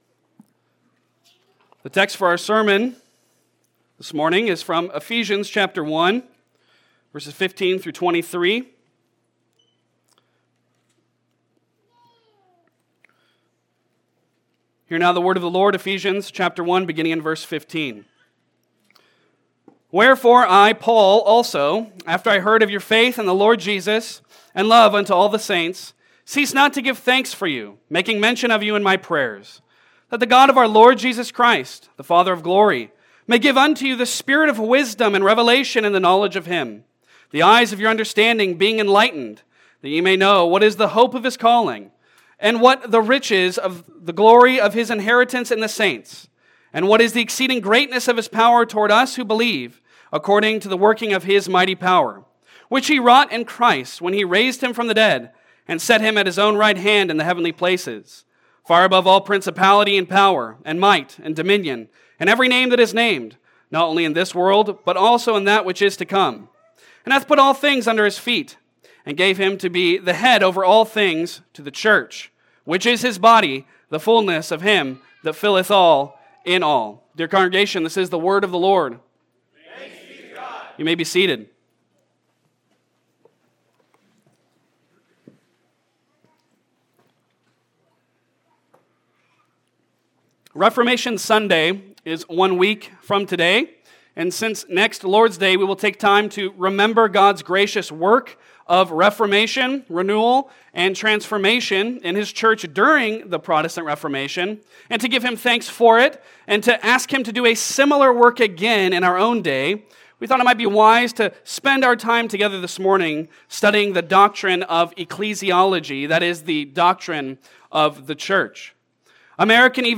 Passage: Ephesians 1:15-23 Service Type: Sunday Sermon Download Files Bulletin « Holy Protection